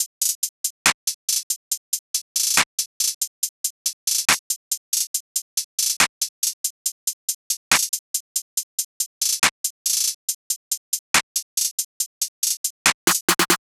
SOUTHSIDE_beat_loop_herb_top_02_140.wav